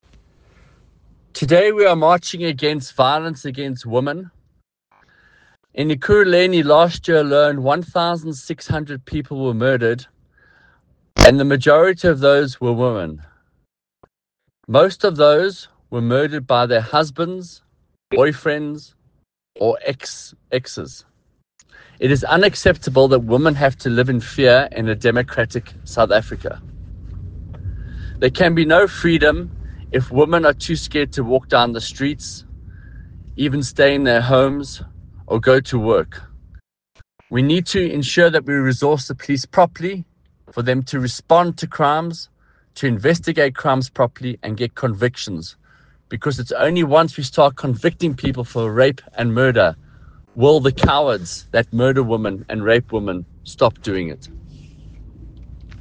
Note to Editors: Please find English soundbite
Michael-Waters-MPL_ENG_Ekurhulenis-shocking-crime-stats.mp3